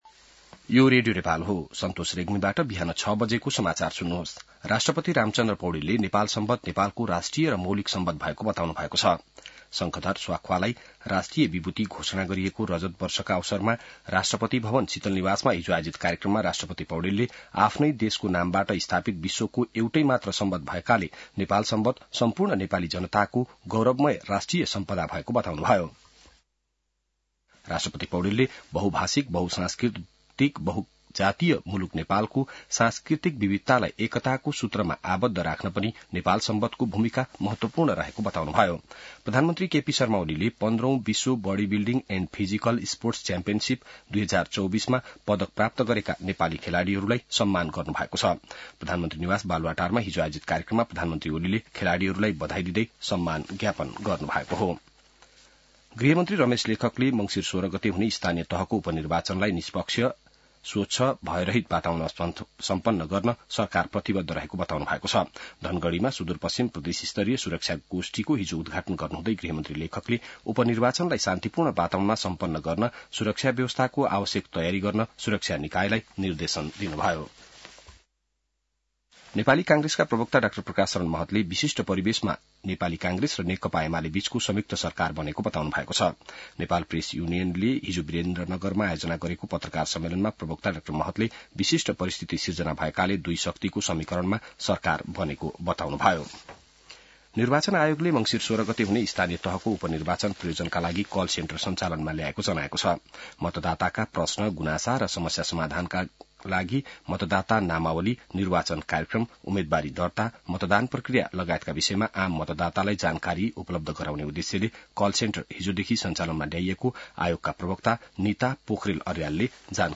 बिहान ६ बजेको नेपाली समाचार : ६ मंसिर , २०८१